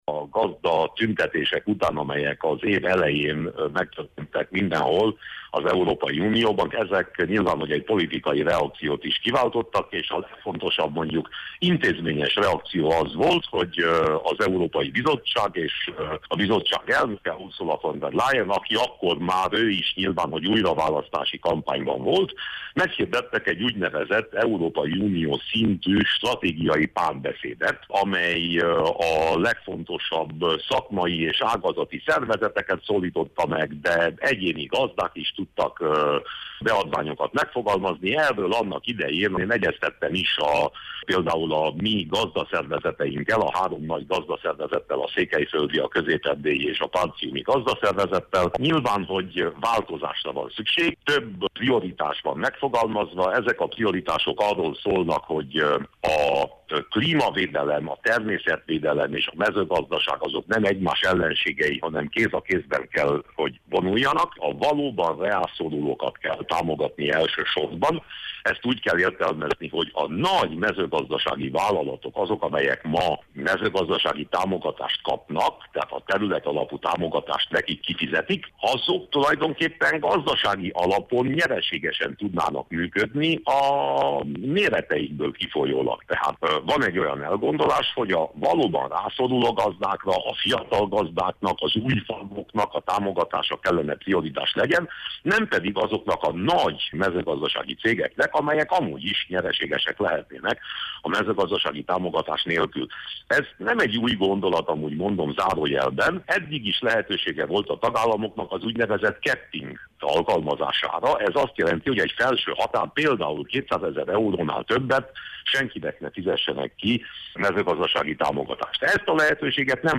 Winkler Gyula EP képviselőt kérdezte